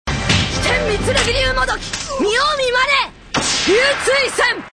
It's hard to say, since he sounds as young as he is ^_^